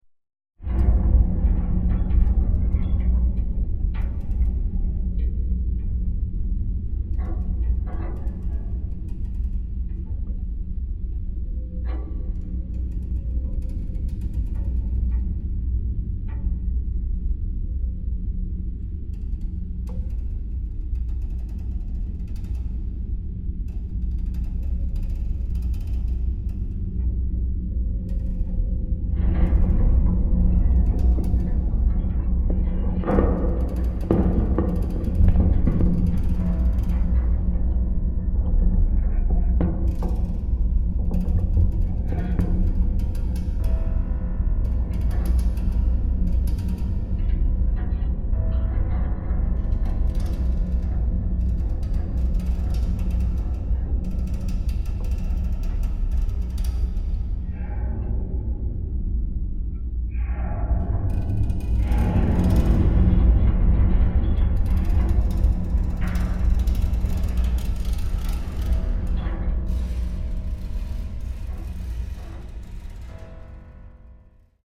for solo piano, transducers, and field recordings